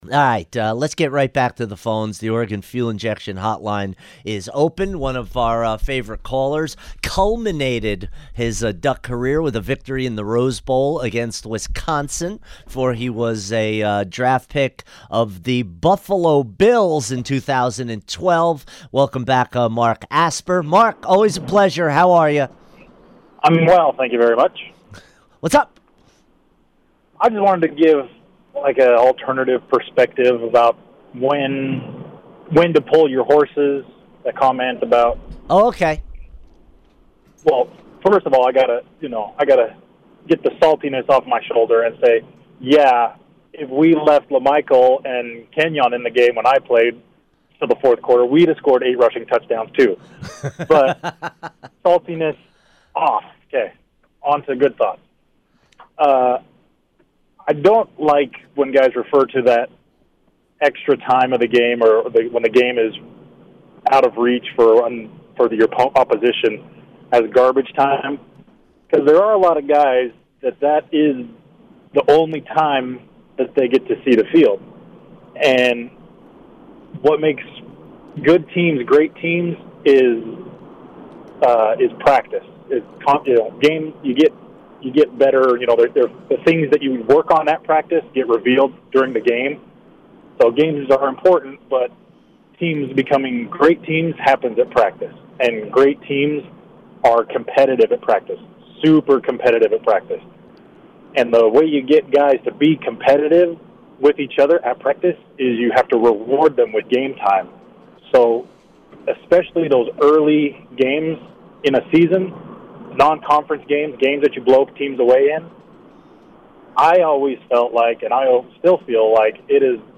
called into the hotline to talk about what it was like playing in blowouts, tales from the sideline and more.